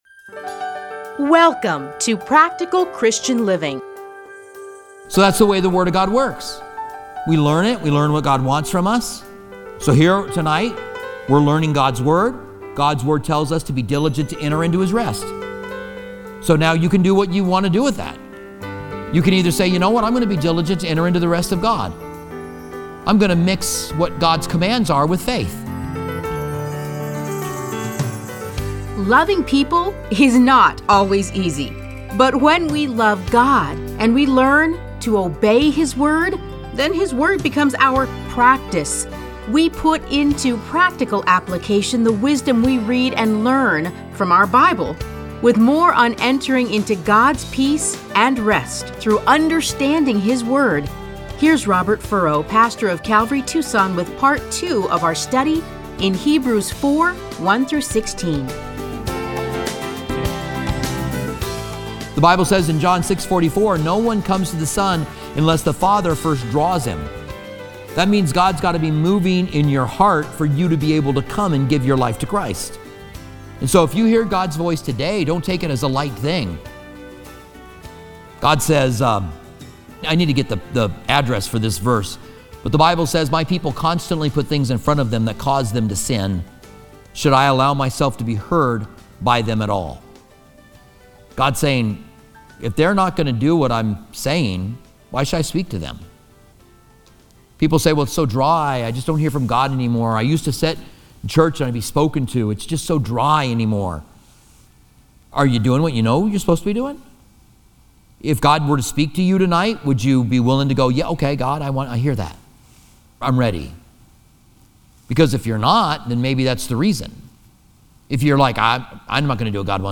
Listen to a teaching from Hebrews 4:1-16.